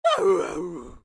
AV_dog_exclaim.ogg